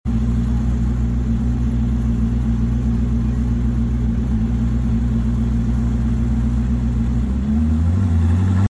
The Rumble Of A Jesko Sound Effects Free Download